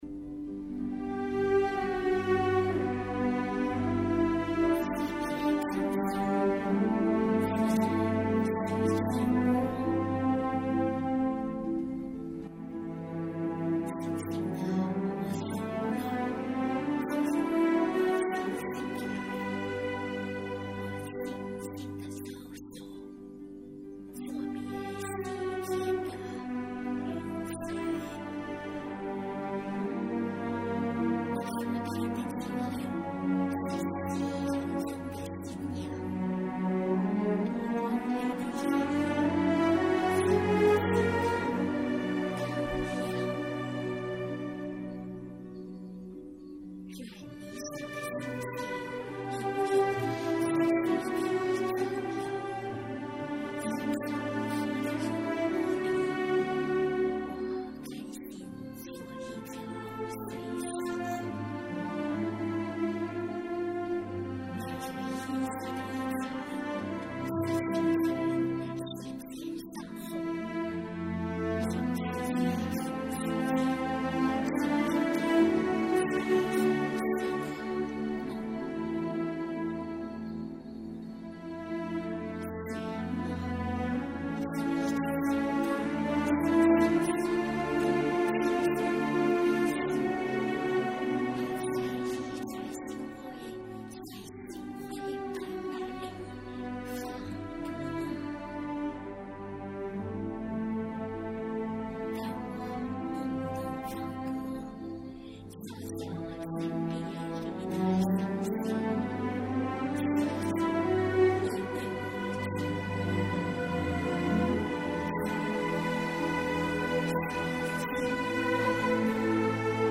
字正腔圆的主播级朗诵！